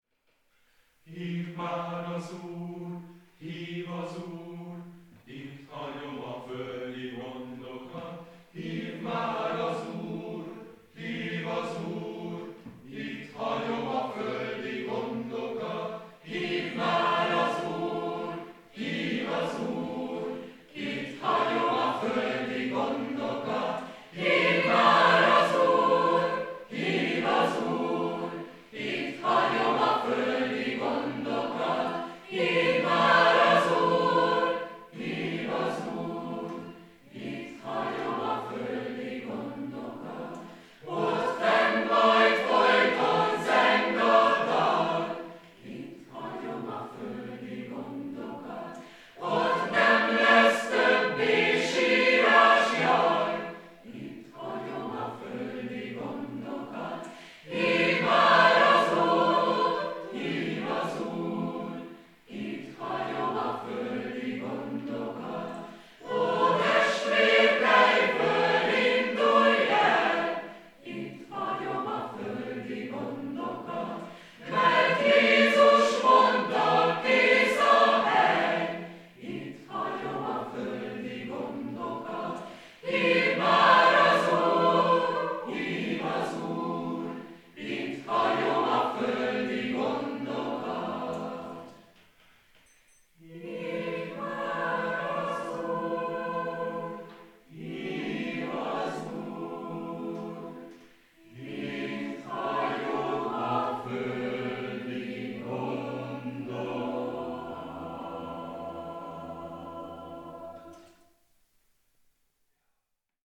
Dallam: Spirituálé.
Ez a spirituálé könnyen megvalósítható négyszólamúságot kínál.